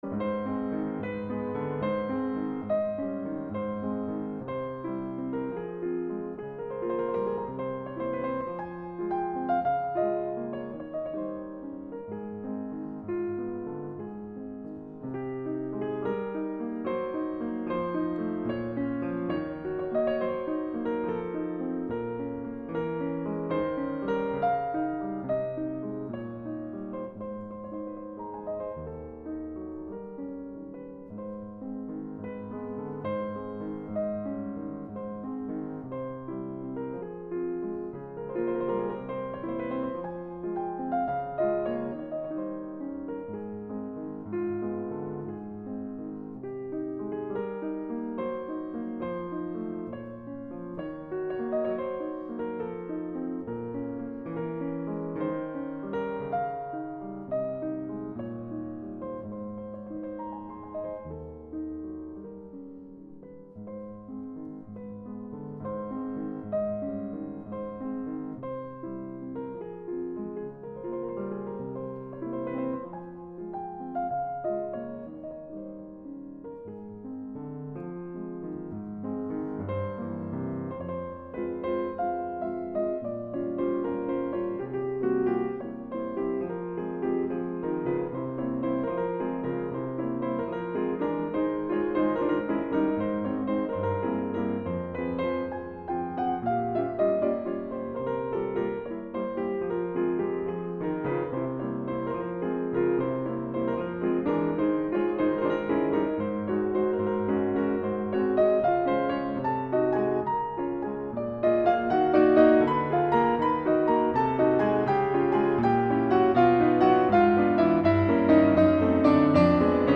"Notturno n. 10" in La bemolle maggiore da opera 32 n. 2 del 1837.
Pianista
Chopin_ Nocturne_10inA-FlatMajor_Op 32_ 2.mp3